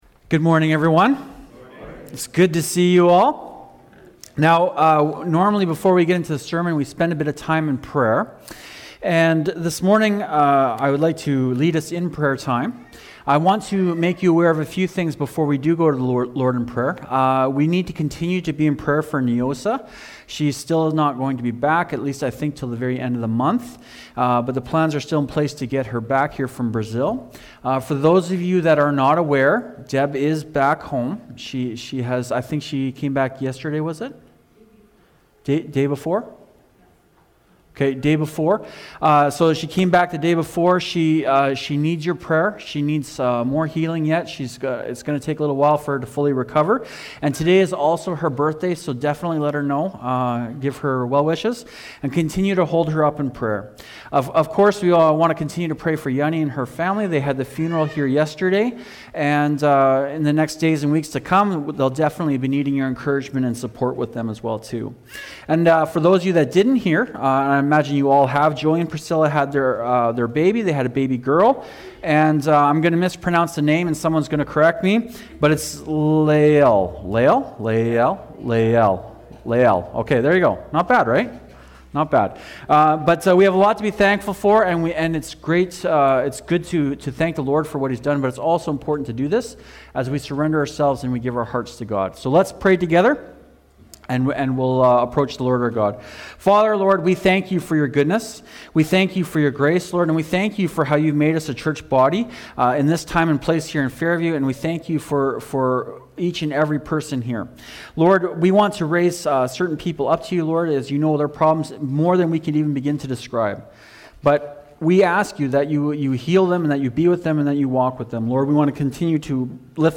Fathers-Day-Sermon-Audio.mp3